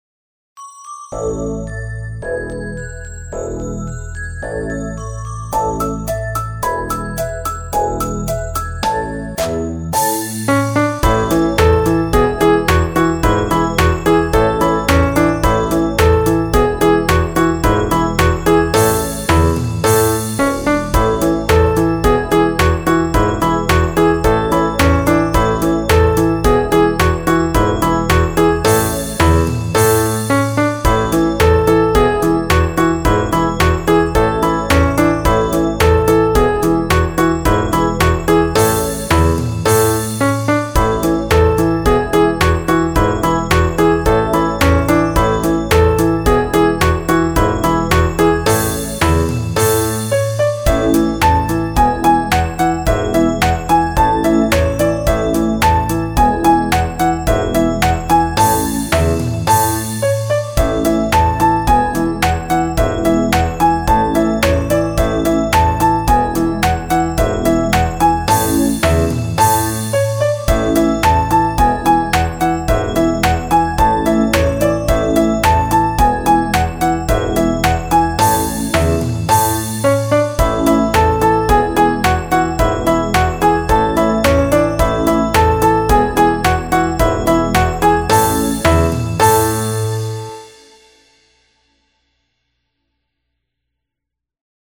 Children’s song, Spain.
Commercial License (Spanish karaoke)